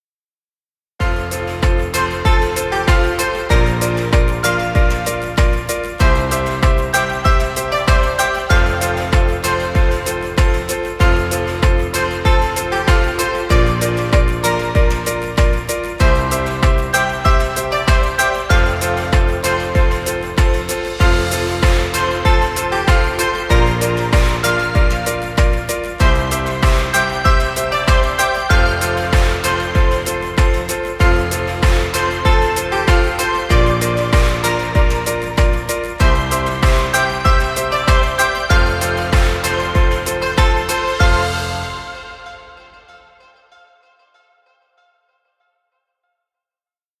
Children’s music.